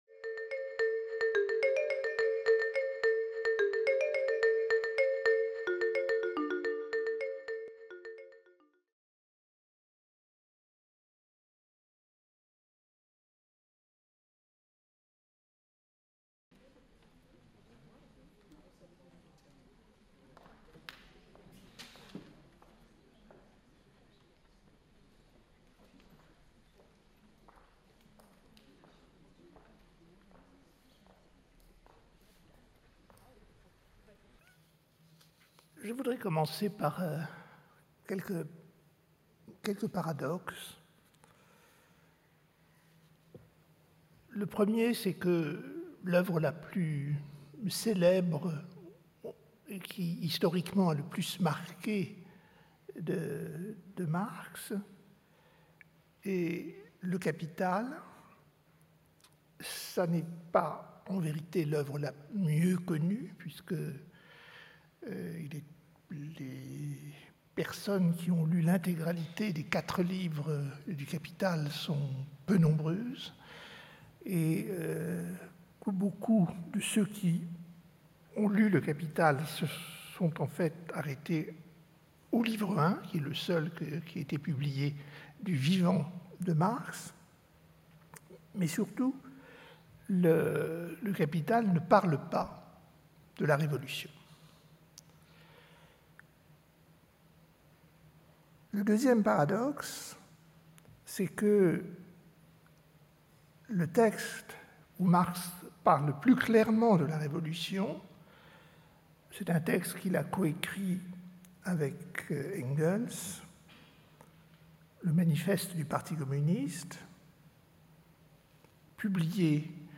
Conférence de l’Université populaire du quai Branly (UPQB), donnée le 28 septembre 2016 Les Grandes Révoltes : Ce cycle analyse les grands mouvements de révoltes à l'origine de bouleversements politiques et sociaux, et qui restent, encore aujourd'hui, gravés dans nos cultures.